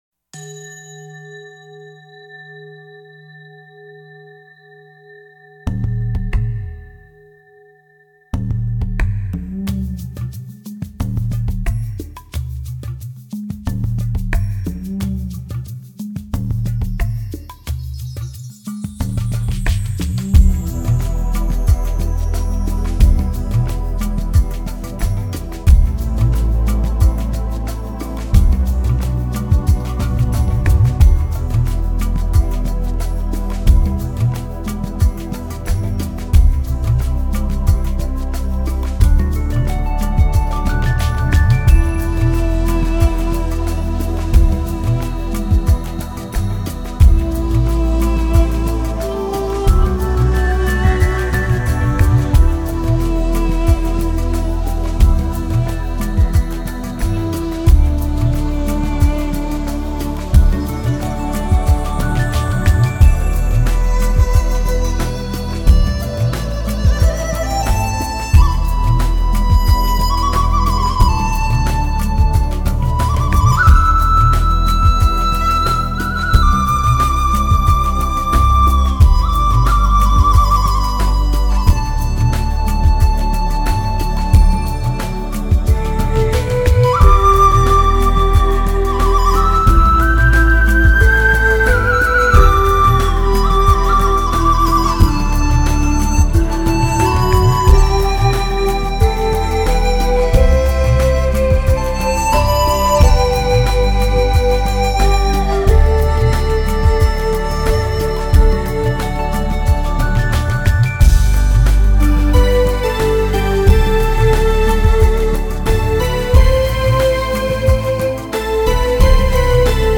Нью эйдж New age